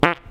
coal_funny.wav